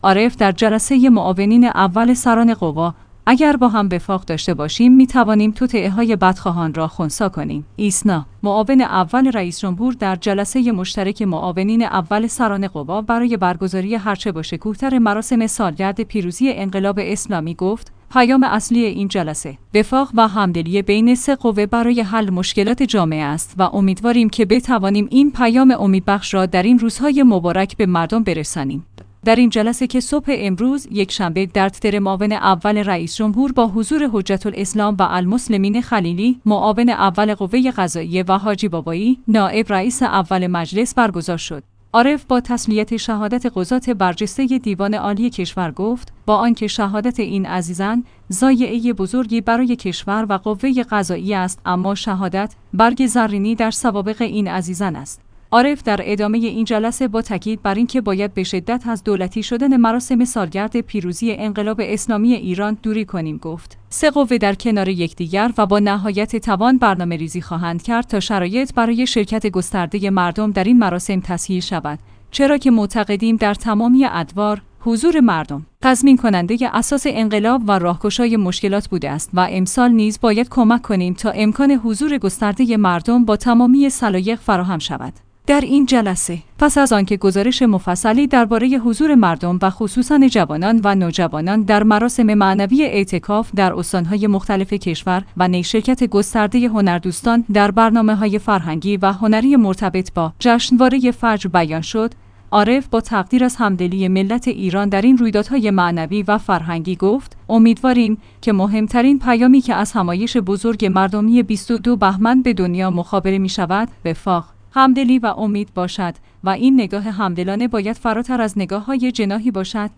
آخرین خبر | عارف در جلسه معاونین اول سران قوا: اگر با هم وفاق داشته باشیم می‌توانیم توطئه‌های بدخواهان را خنثی کنیم